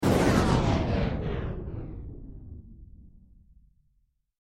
На этой странице собраны звуки резкого отключения электричества – от тихого щелчка выключателя до гула пропадающего напряжения в сети.